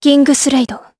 Tanya-Vox_Kingsraid_jp_b.wav